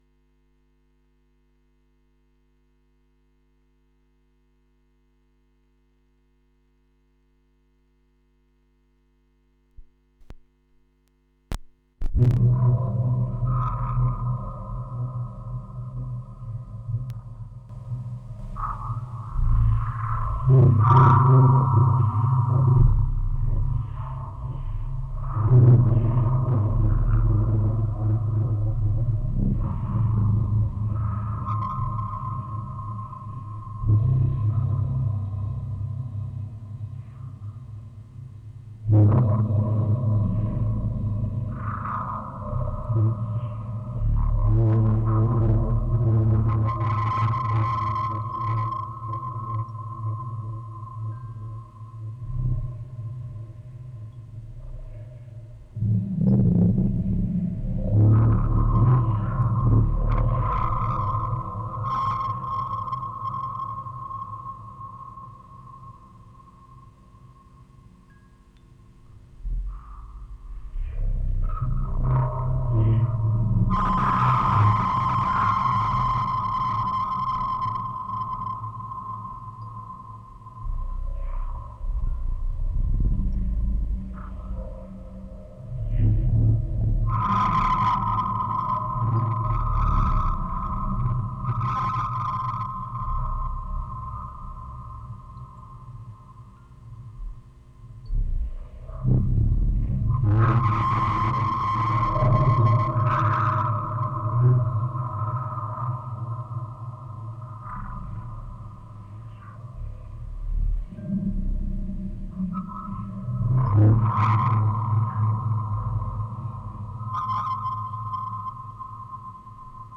1. Aufnahme (Tape out).mp3